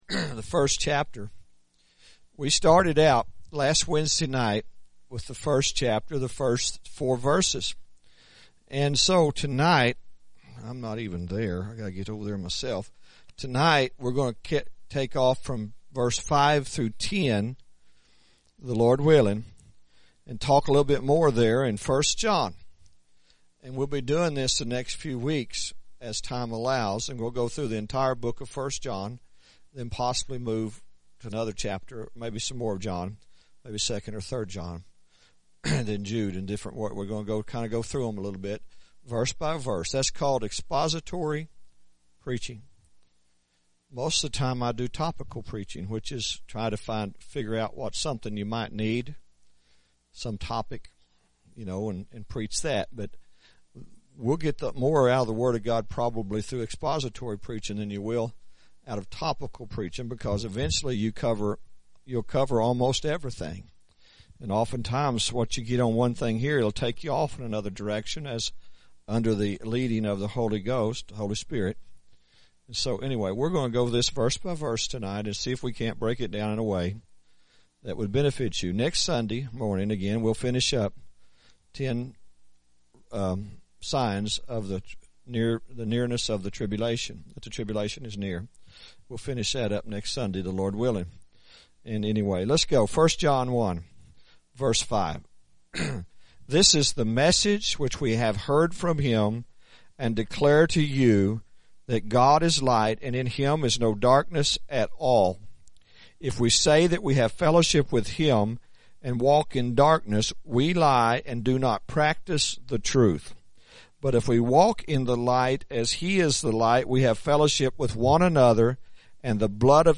1 John Series – Sermon 2